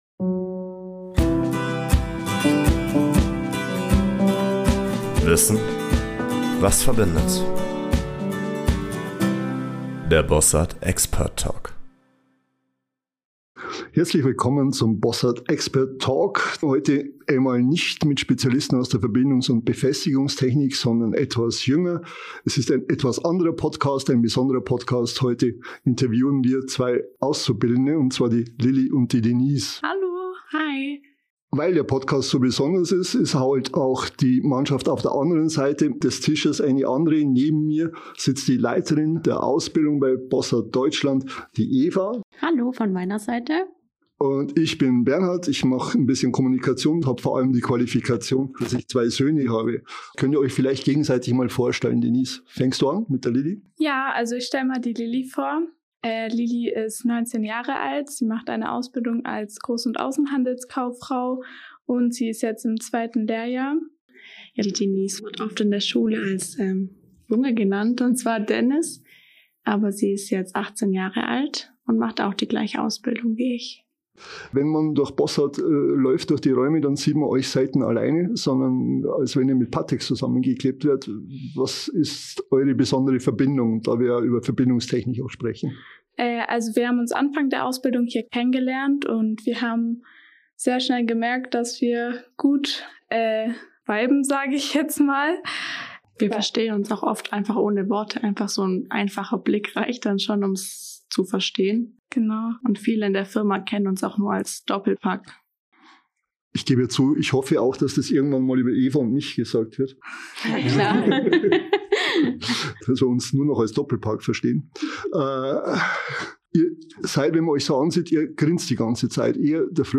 Azubi-Alltag bei Bossard: In dieser Special-Folge übernehmen die Auszubildenden den Podcast und platzieren auch gleich ein neues Moderatoren Duo. Authentisch, humorvoll und mit spannenden Einblicken in die Ausbildung bei Bossard Deutschland - und in das Teamplay der nächsten Generation.